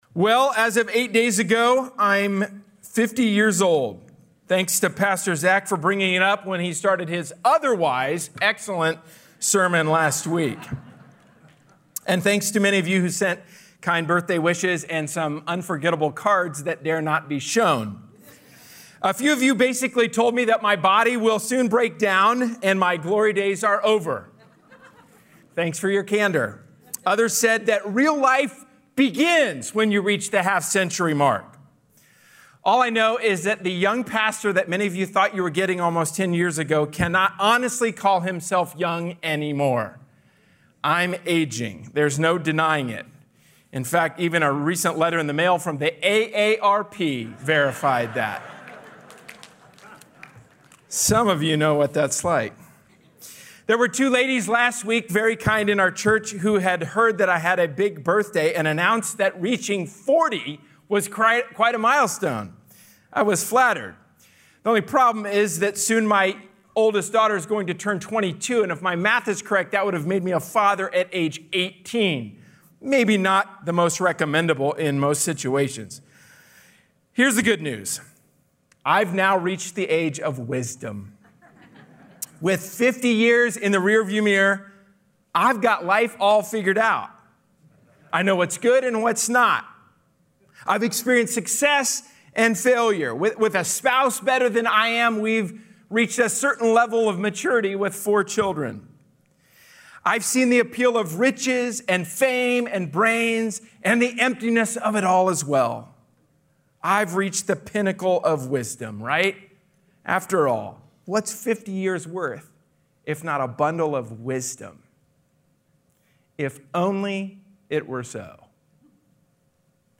A sermon from the series "James: Faith/Works."